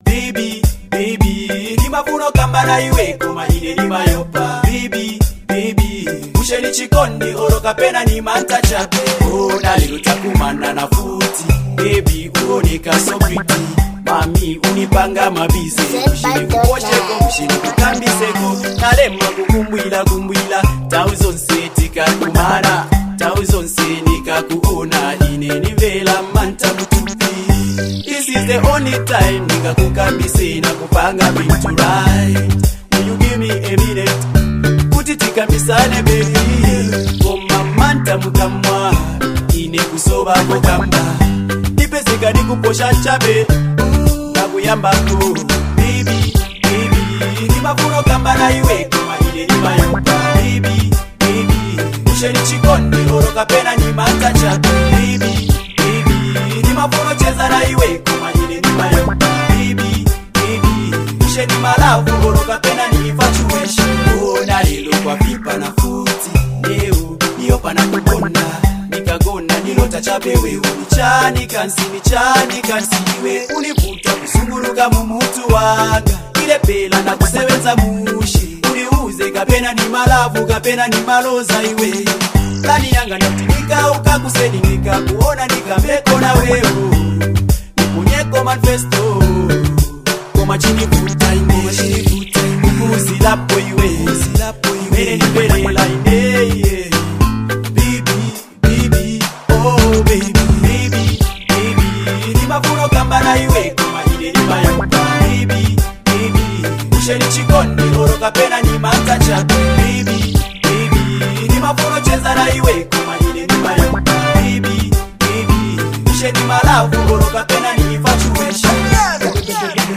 heartfelt tune